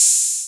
puredata/resources/808_drum_kit/hihats/808-OpenHiHats06.wav at master
808-OpenHiHats06.wav